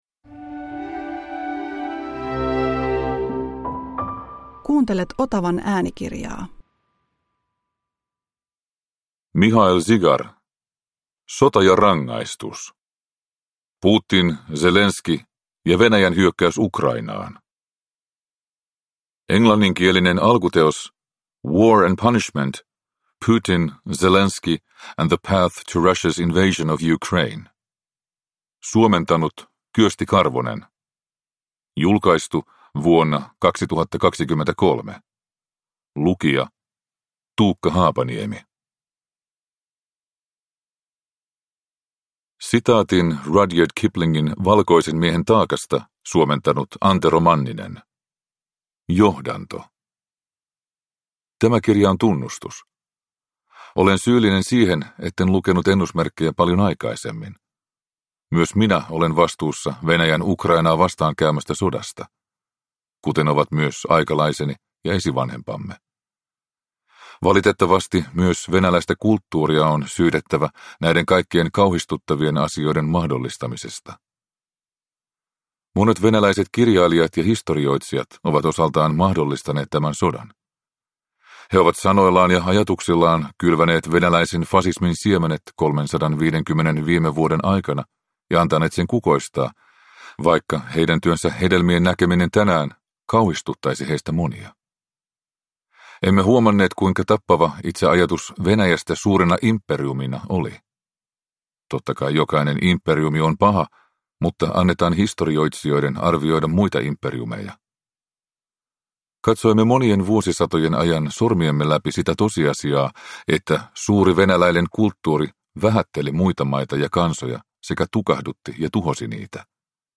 Sota ja rangaistus – Ljudbok – Laddas ner